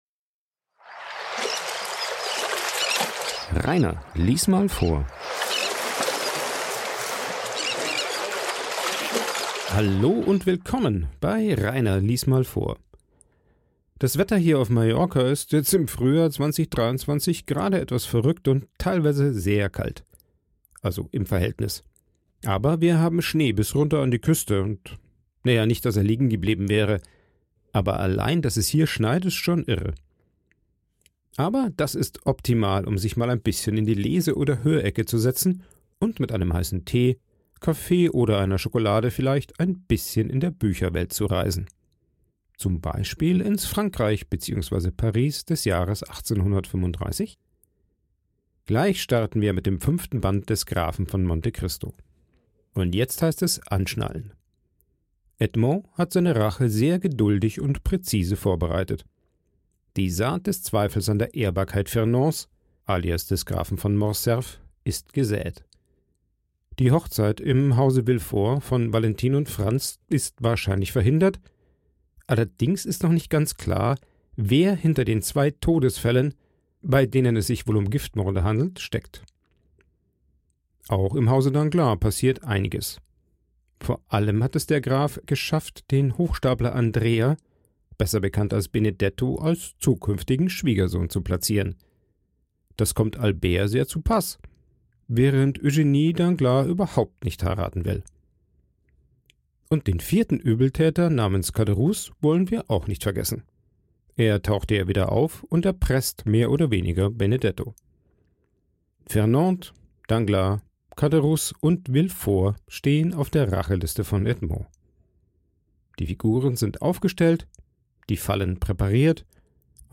Der Vorlese Podcast
Ein Vorlese Podcast